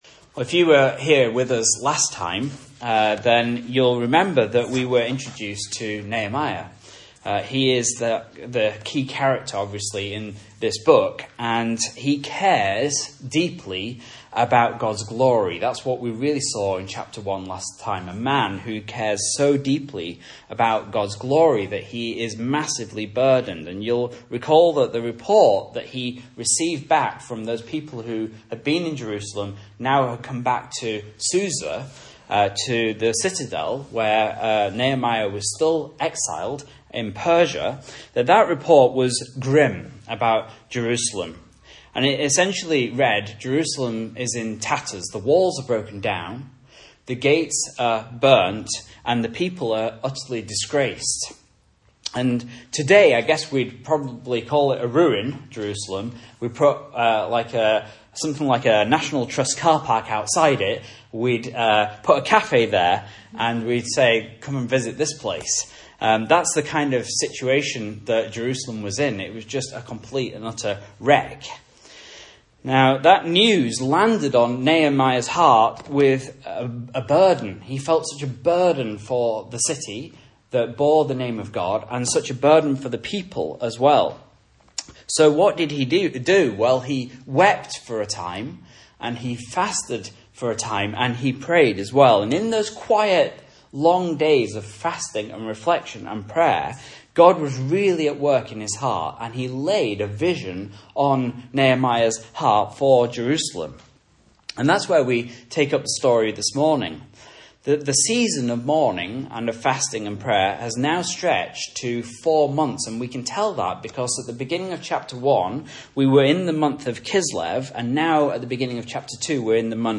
Message Scripture: Nehemiah 2 | Listen